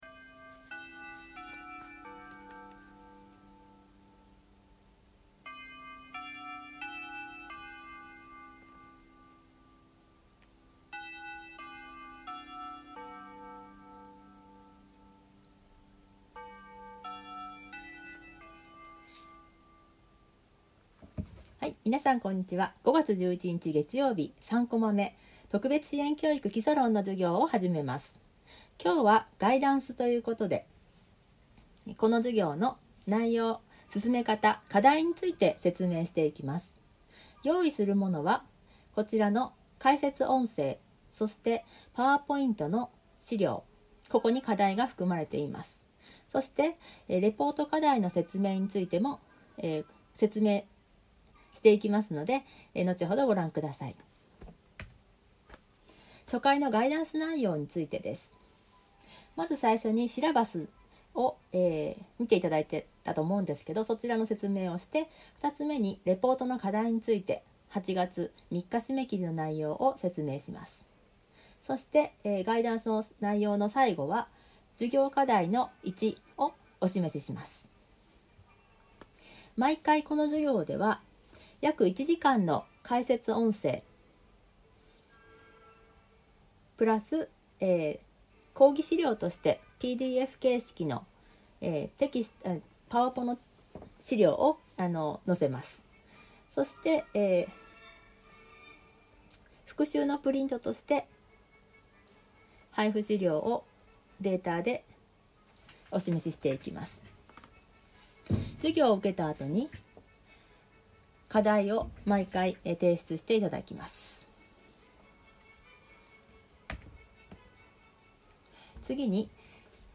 初回サンプルは、こちらから↓ 5月11日 月３解説音声.mp3 5月11日 月３ 授業パワポ.pdf